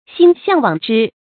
心向往之 注音： ㄒㄧㄣ ㄒㄧㄤˋ ㄨㄤˇ ㄓㄧ 讀音讀法： 意思解釋： 對某個人或事物心里很向往。